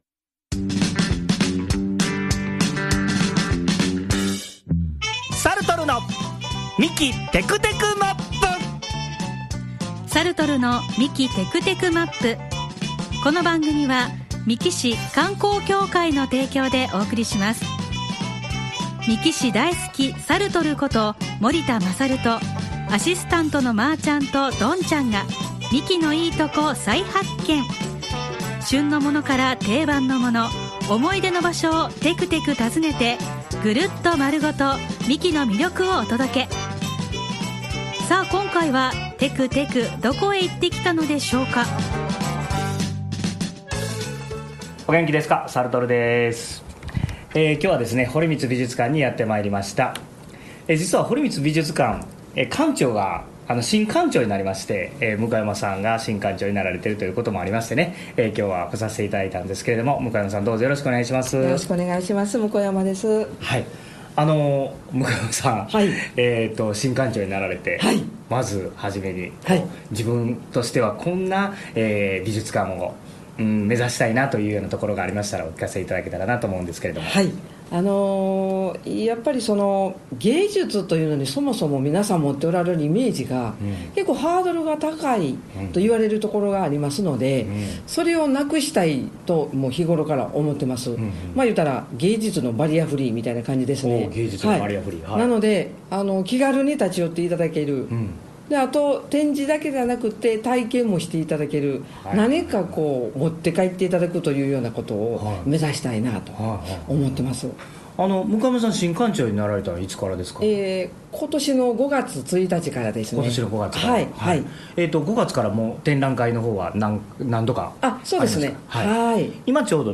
7月後半は、堀光美術館をてくてくしてきました。